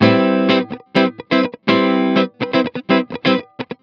01 GuitarFunky Loop A.wav